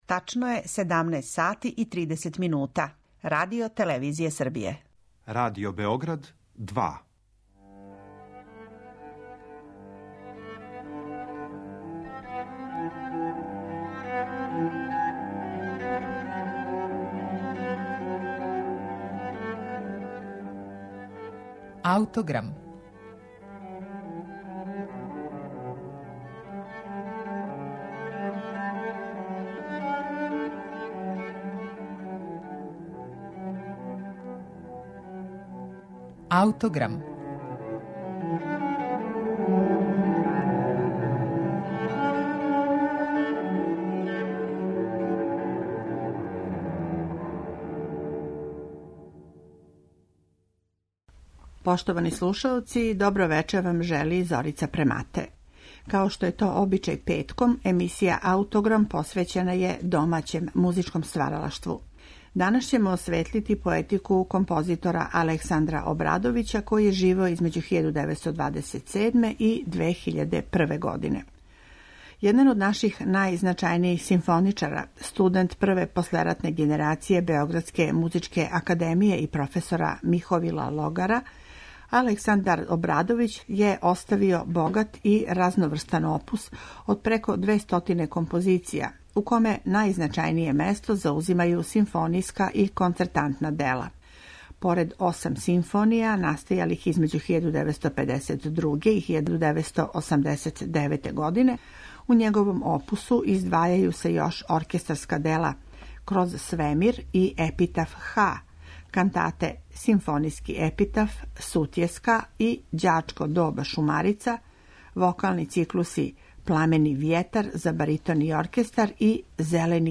Петком је емисија 'Аутограм' традиционално посвећена домаћем музичком стваралаштву.